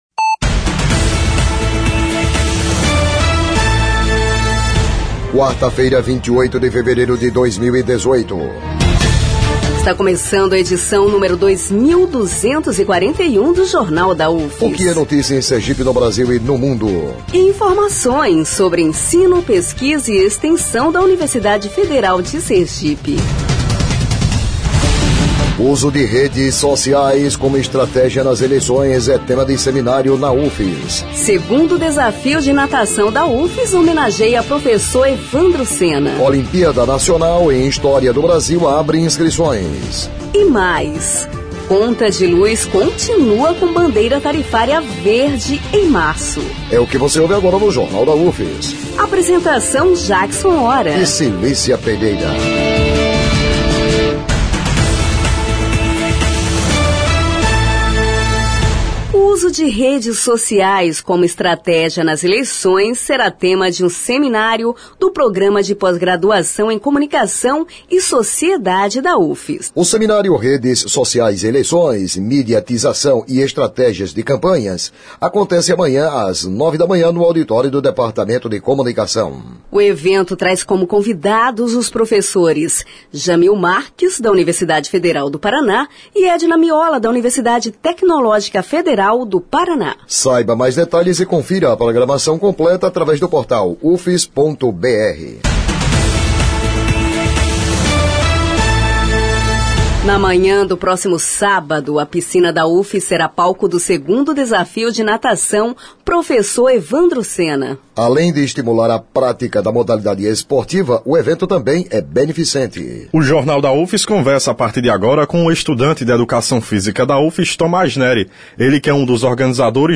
O noticiário vai ao ar às 11h na Rádio UFS FM, com reprises às 17h e 22h.